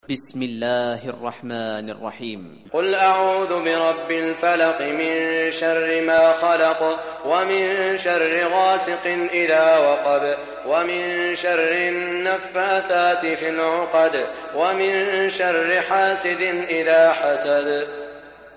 Surah Falaq Recitation by Sheikh Saud Shuraim
Surah Falaq quran tilawat / recitation in the beautiful voice of Sheikh Saud al Shuraim.